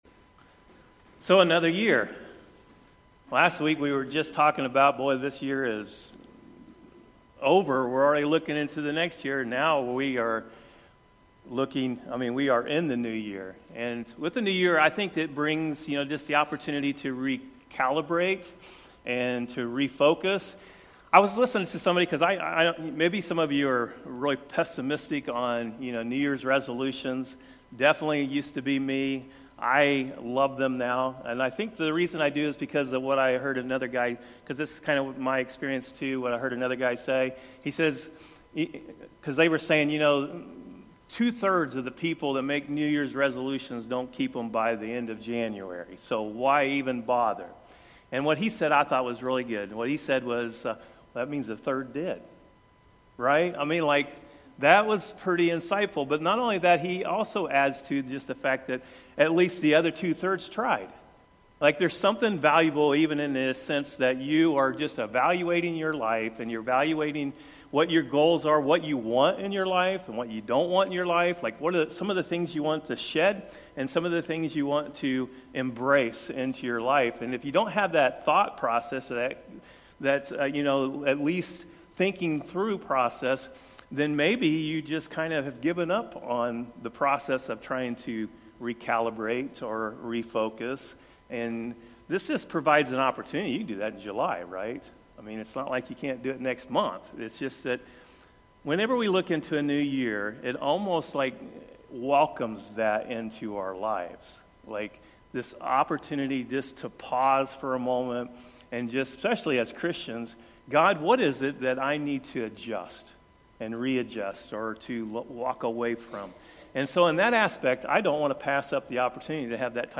Sunday Service, Dec. 29, 2024.
Sermons by Westside Christian Church